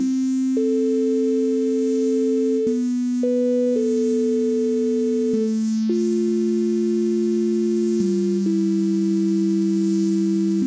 chords.mp3